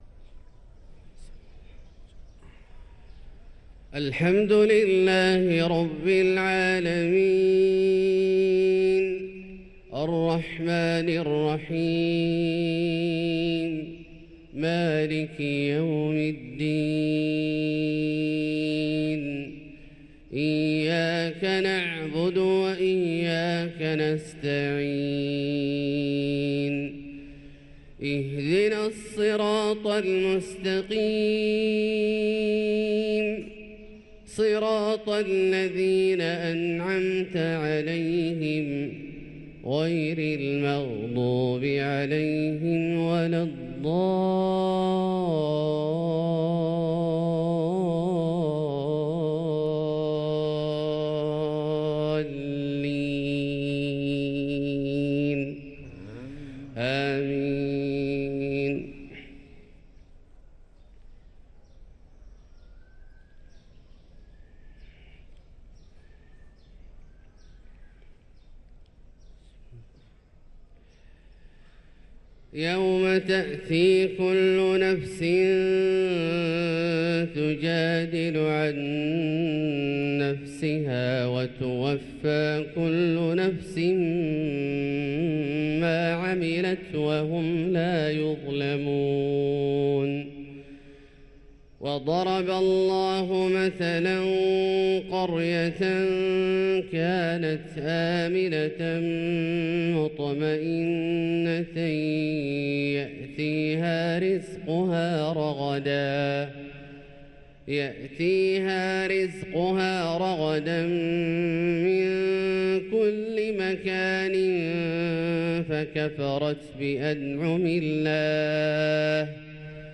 صلاة الفجر للقارئ عبدالله الجهني 14 ربيع الآخر 1445 هـ